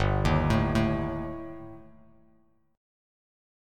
Gsus2#5 Chord